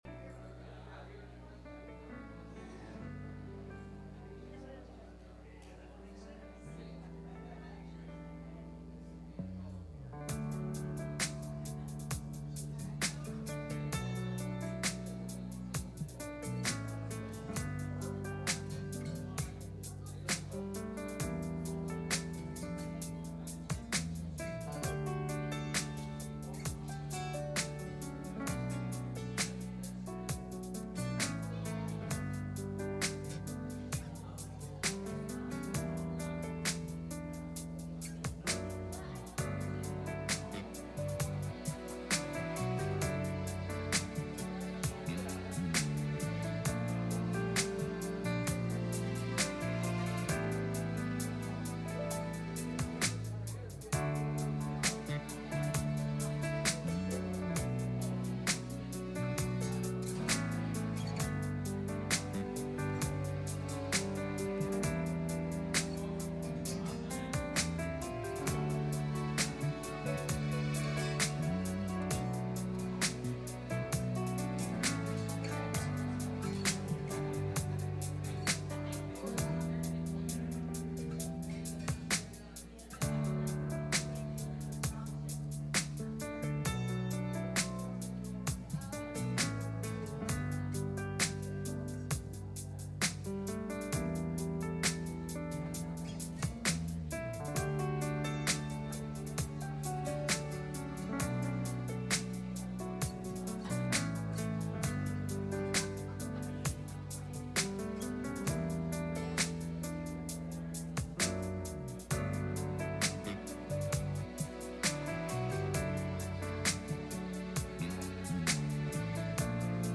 Galatians 5:25 Service Type: Sunday Morning « Time To Move